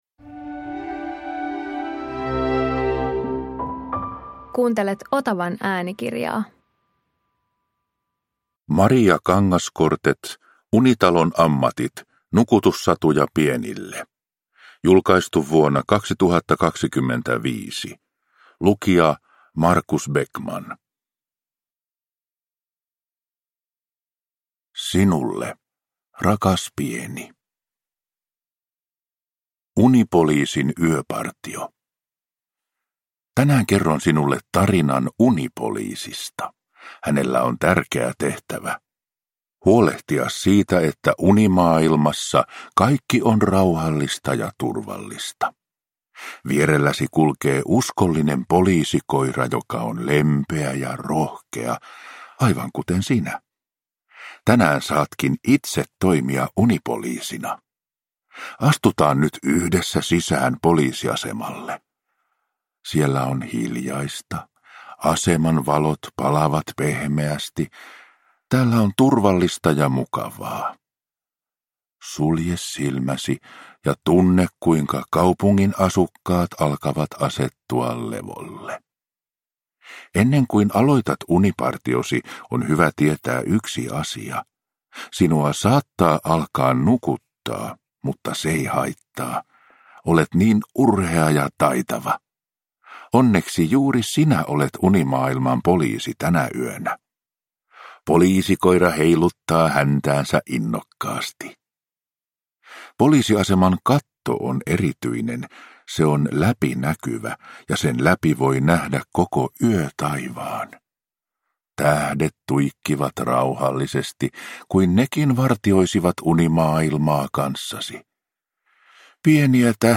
Unitalon ammatit – Ljudbok
Viisi lumoavan meditatiivisesti kerrottua tarinaa auttavat lasta vaipumaan rauhaisaan uneen.